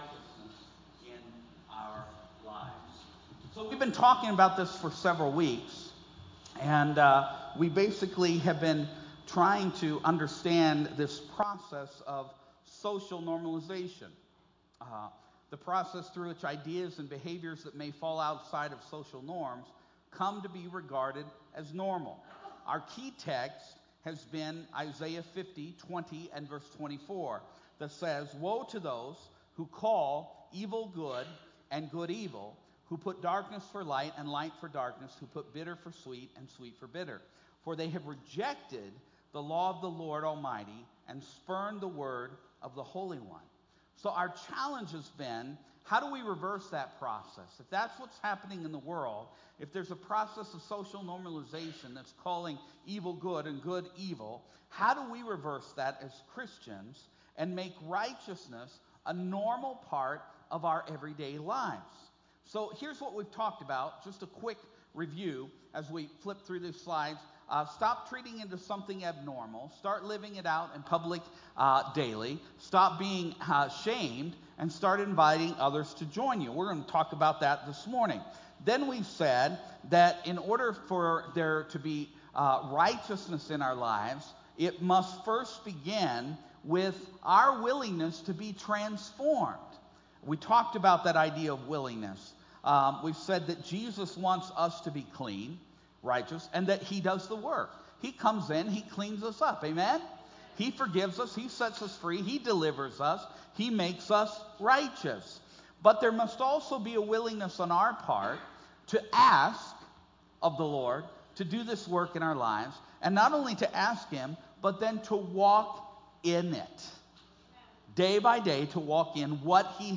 Today we talked about the first 5 Categories that we hope to find practical ways to live righteously in our everyday lives. Some portions of the recording are low due to the fact that this was an interactive service with participation from the audience.
Sermon